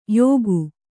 ♪ yōgu